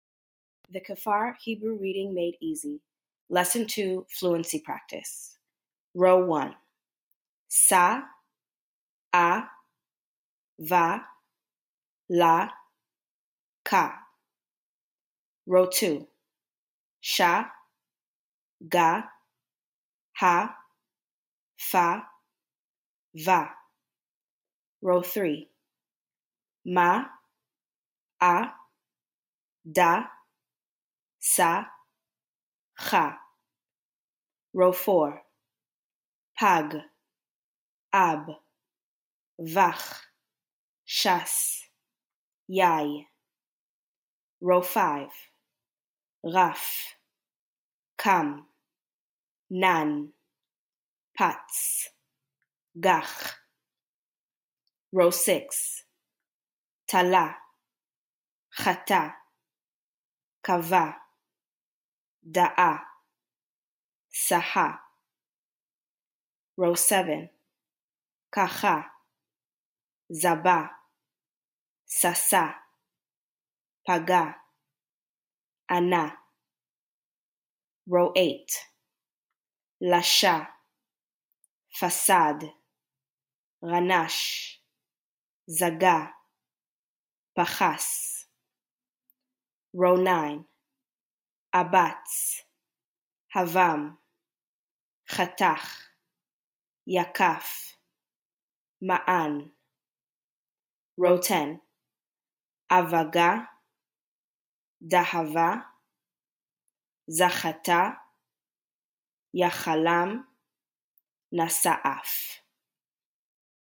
If you're unsure of a word's pronunciation, want to hear it read to you, or just need additional support, you can play the audio file.